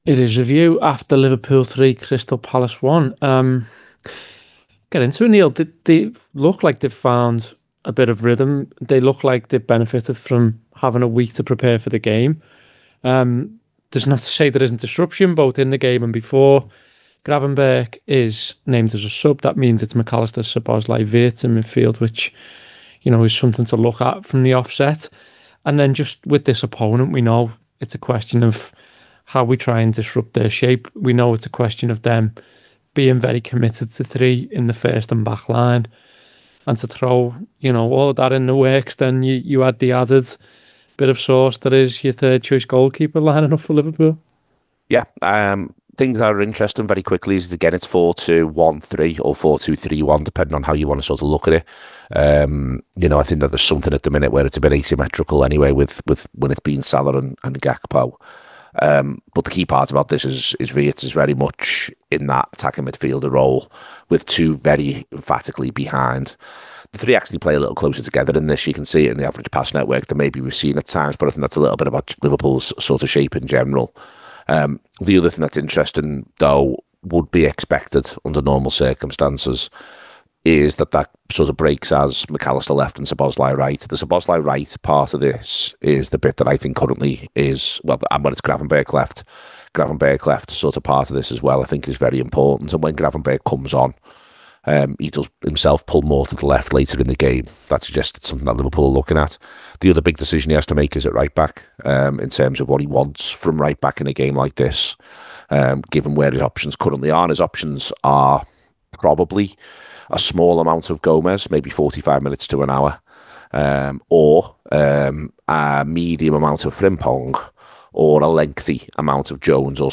Below is a clip from the show – subscribe to The Anfield Wrap for more review chat…